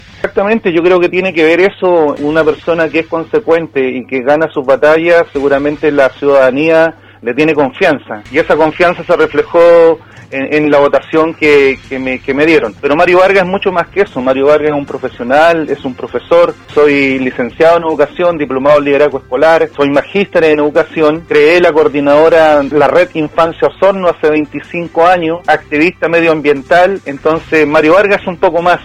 En conversación con Radio Sago, Mario Vargas, quien será parte de la convención constitucional por el Distrito 25, dio cuenta de los desafíos que se vendrán a futuro y lo que se espera plasmar en la redacción de la nueva carta magna.